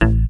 cch_bass_one_shot_hurley_A.wav